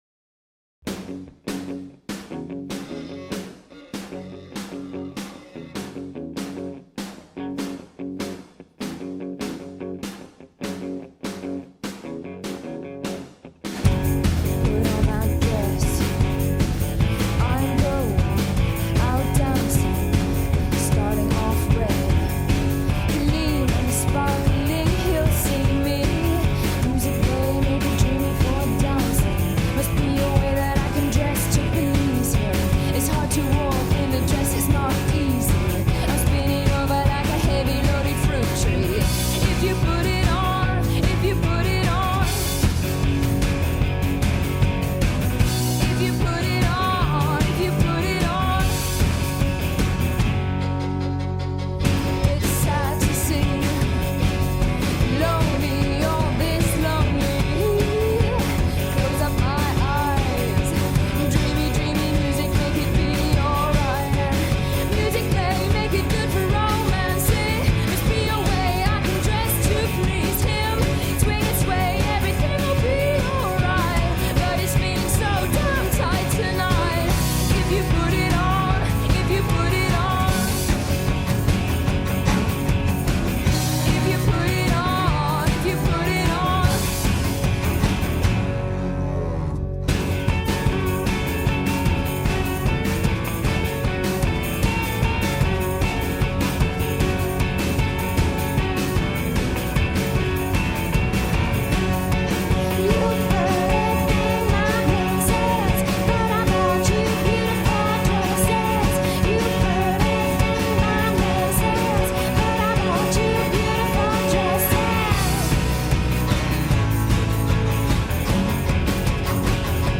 A lone creeping guitar scratches out a rhythm.